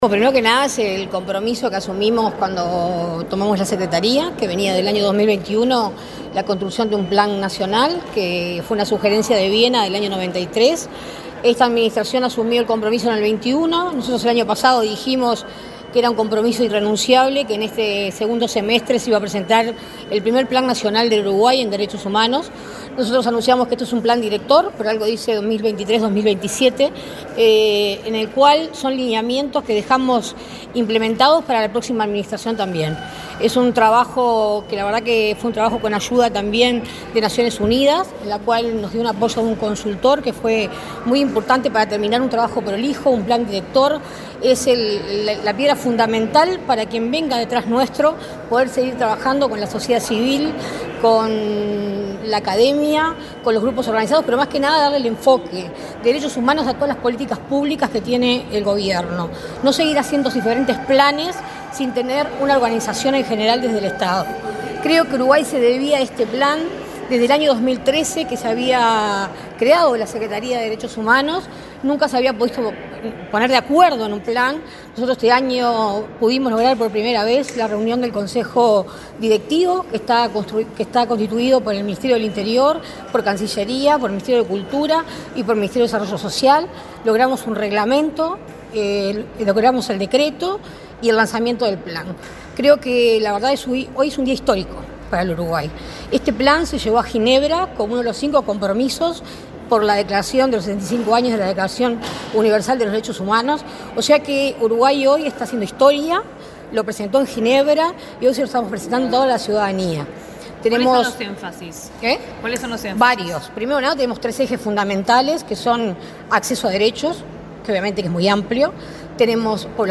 Declaraciones de la titular de la Secretaría de Derechos Humanos, Sandra Etcheverry 18/12/2023 Compartir Facebook X Copiar enlace WhatsApp LinkedIn La titular de la Secretaría de Derechos Humanos, Sandra Etcheverry, dialogó con la prensa, este lunes 18 en la Torre Ejecutiva, luego de participar en la presentación del primer Plan Nacional de Derechos Humanos de Uruguay.